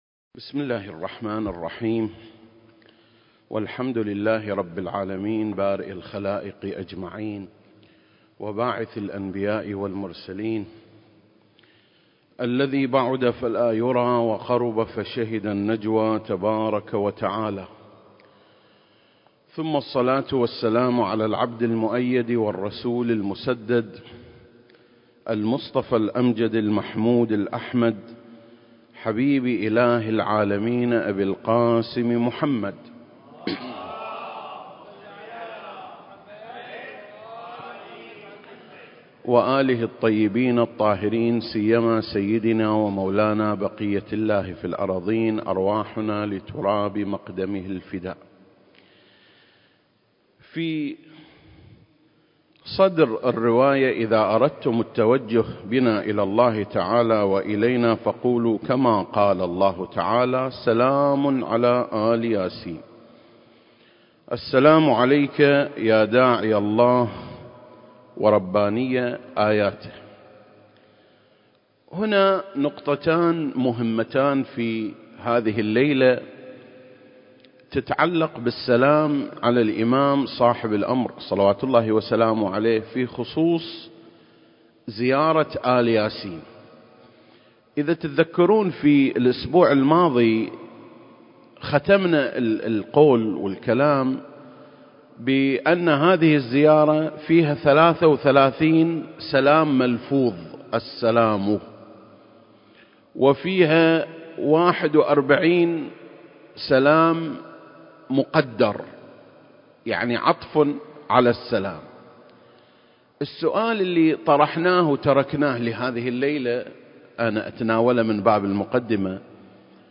سلسلة: شرح زيارة آل ياسين (20) - آل ياسين (1) المكان: مسجد مقامس - الكويت التاريخ: 2021